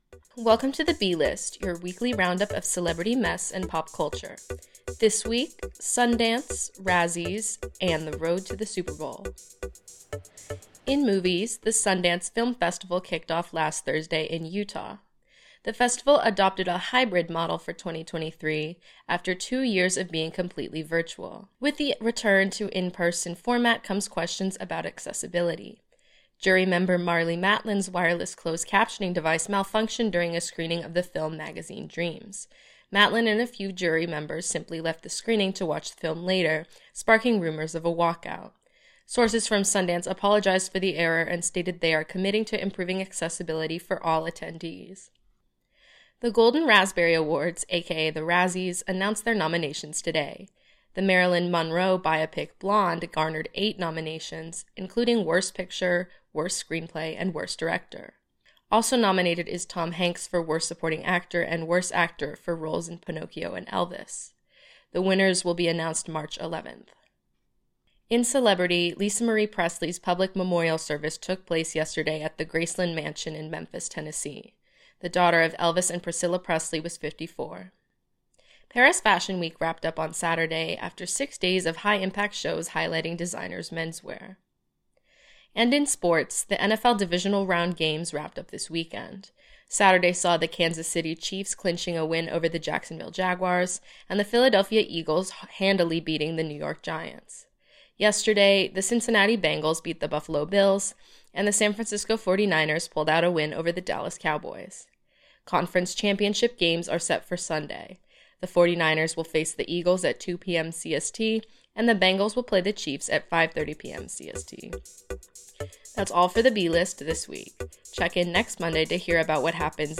It’s Monday night, so you know what that means: time for a new edition of The B-List, WNUR News’s weekly pop culture briefing.